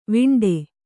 ♪ viṇḍe